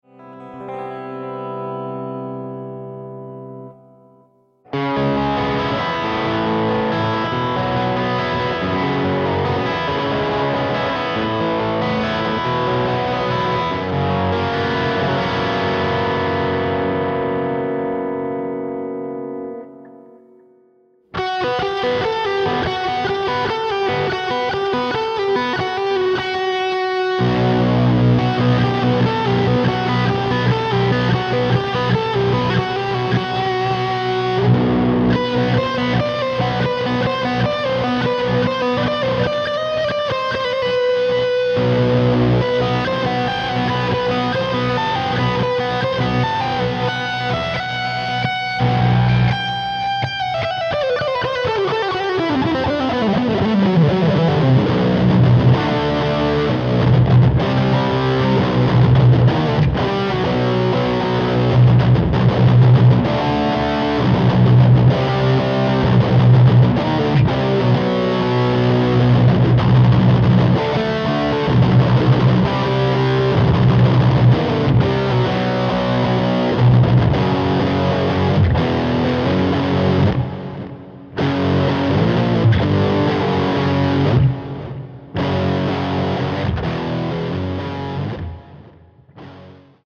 Guitar etc.
Progressive Metal